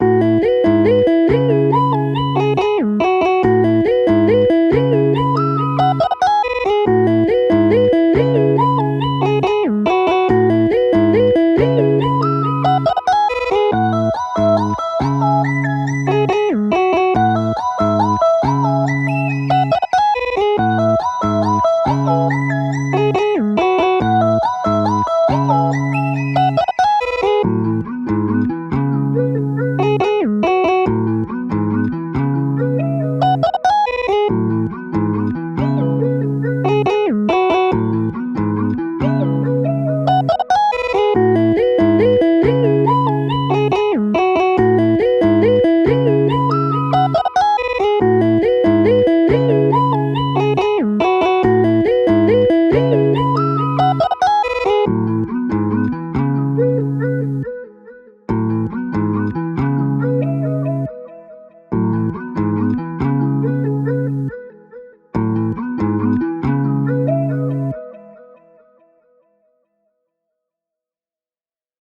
2. Hip Hop Instrumentals